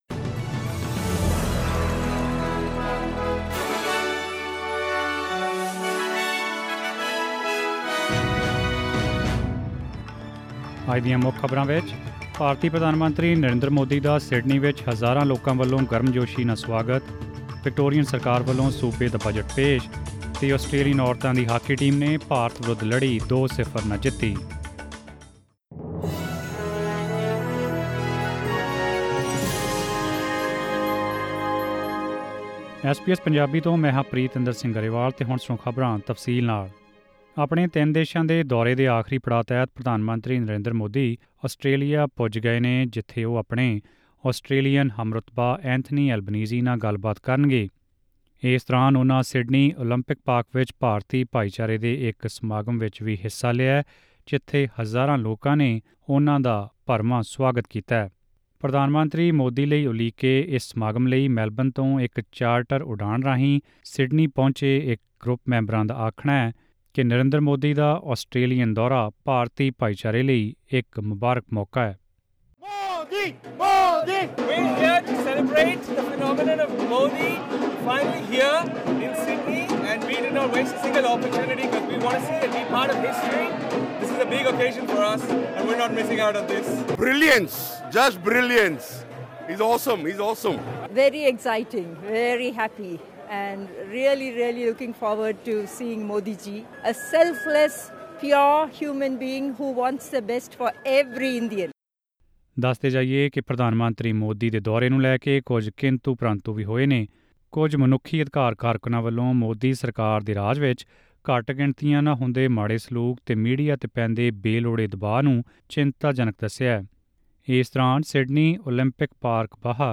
SBS Punjabi Australia News: Tuesday 23 May 2023